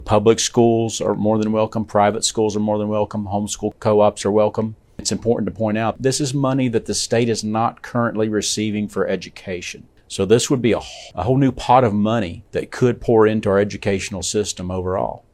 The bill would allow the state to participate in a federal program that provides tax credits for those who make qualifying contributions to scholarship granting organizations.  Here is Senate Education Chairman Steve West.